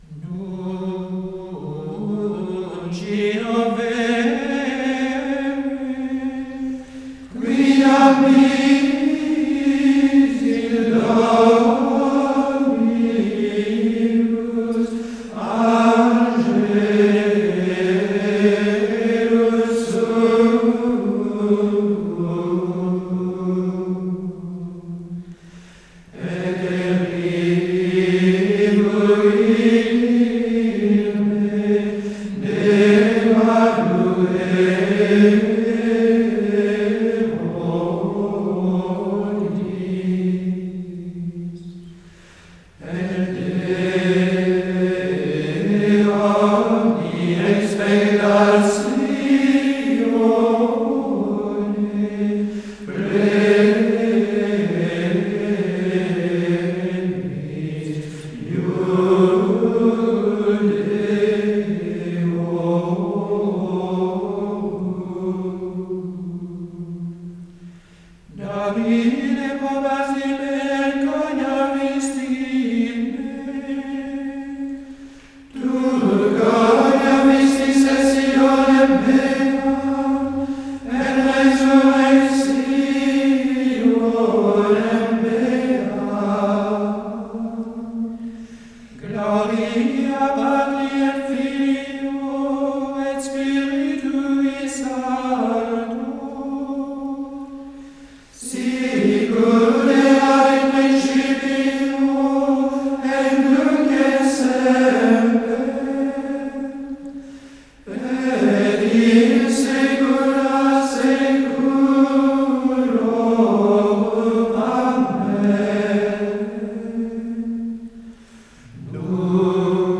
La mélodie de cet introït est empruntée au 3e mode qui est justement un mode extatique.
Il s’agit en effet d’une sorte de récitatif, mais à la première personne du singulier, ce qui lui donne un caractère de témoignage bien vivant.
Trois phrases musicales composent cet introït.
Tout cela est très ferme et très enthousiaste.
L’alternance entre la joie et le recueillement se fait tout naturellement dans un climat de grande gratitude.
Nunc-scio-Solesmes.mp3